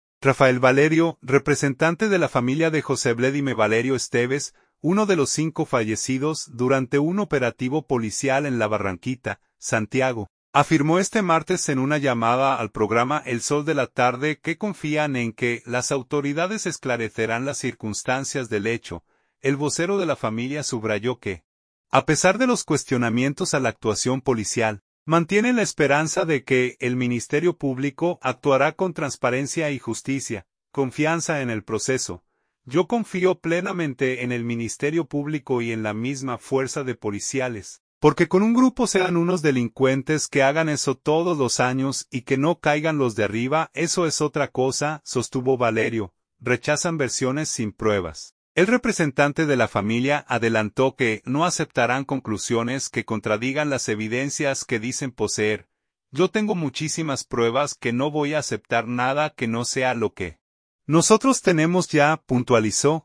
afirmó este martes en una llamada al programa El Sol de la Tarde que confían en que las autoridades esclarecerán las circunstancias del hecho.